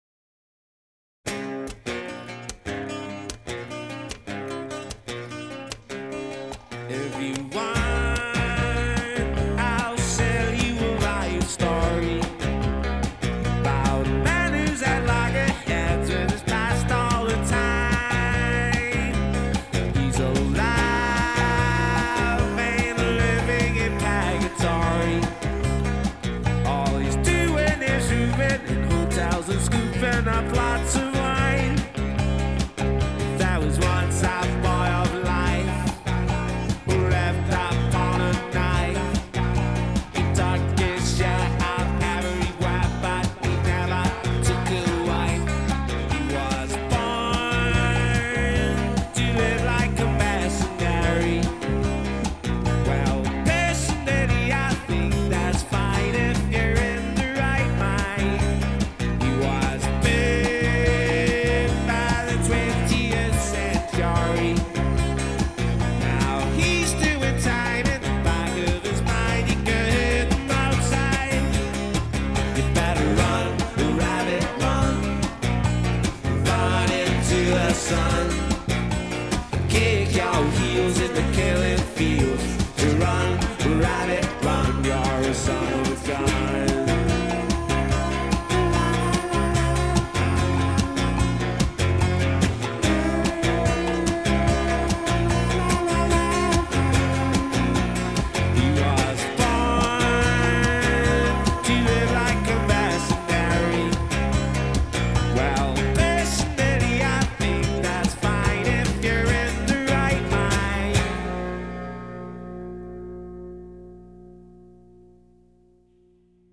rollicking